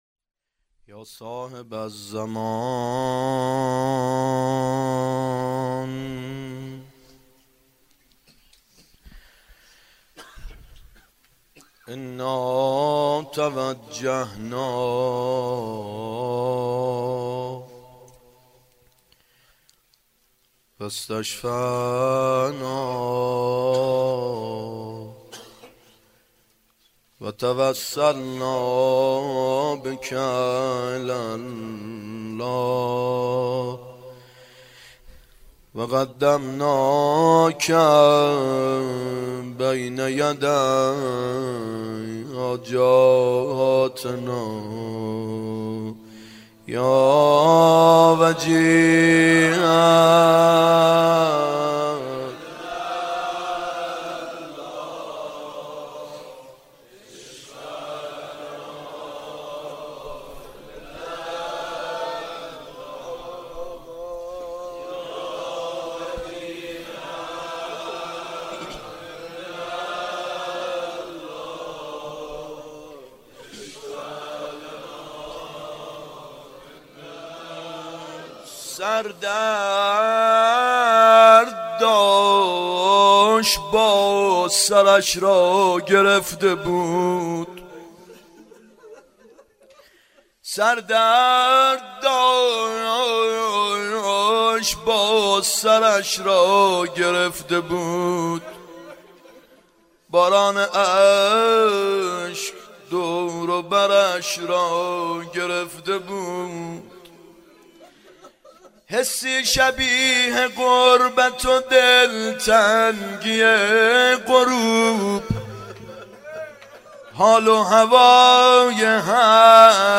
فاطمیه اول هیئت یامهدی (عج)